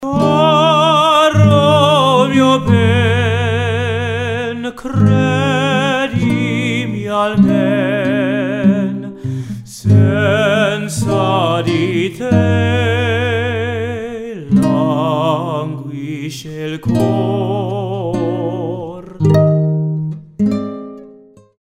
1. 2 Condenser Microphones (I use RØde NT-1a microphones)
They reproduce sound accurately and are the quietest microphones I’ve ever used -meaning no static buzz or microphone hiss that you hear on old tape recordings (or with regular vocal dynamic microphones) for instance.
close-miked recording.